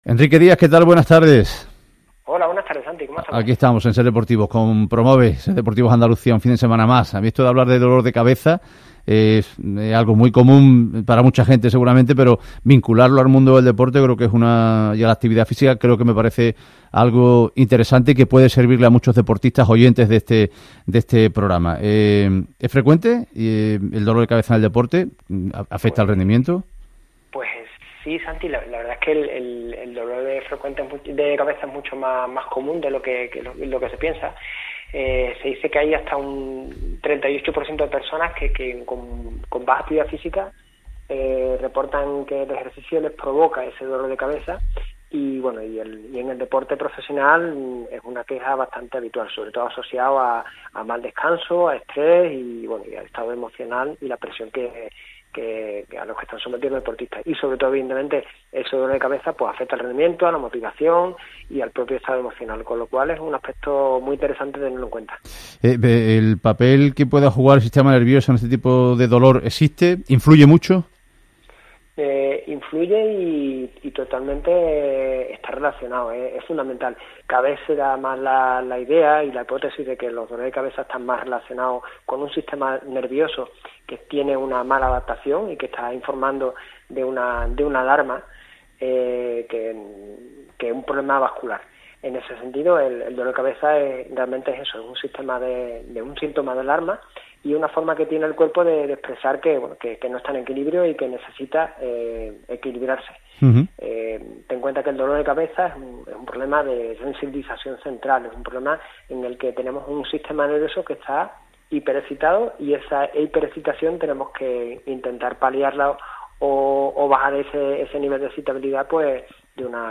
Programa emitido en la cadena SER en Ser Deportivos Andalucía el 11 de mayo de 2025